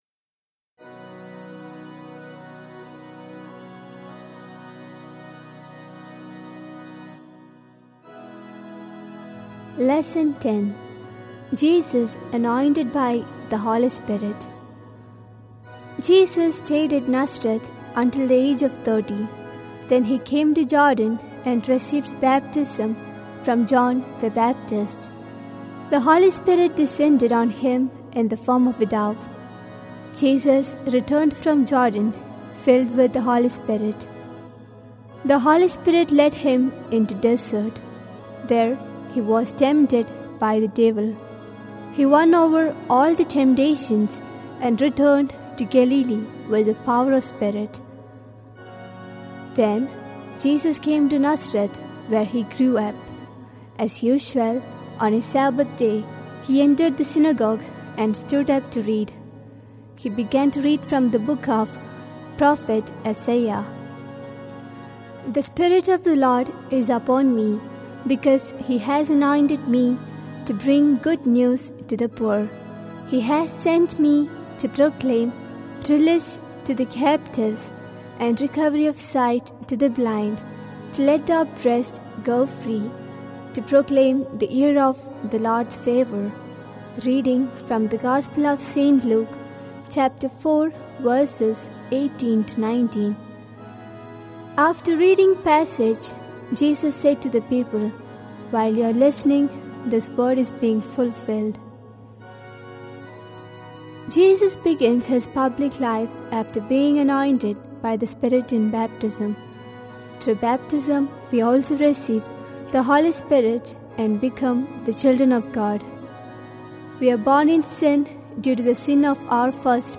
lesson